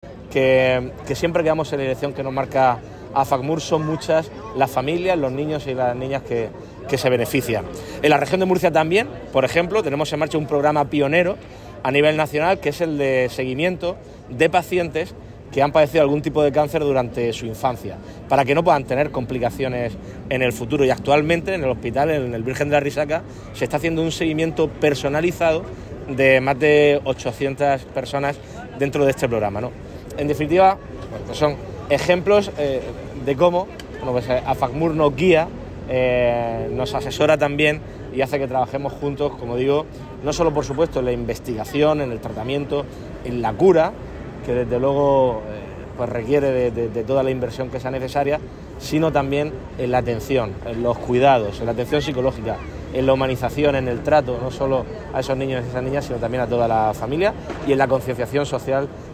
Declaraciones del presidente de Comunidad, Fernando López Miras, en el acto organizado por la Asociación de Familiares de Niños con Cáncer de la Región de Murcia (Afacmur) con motivo del Día Internacional del Cáncer Infantil.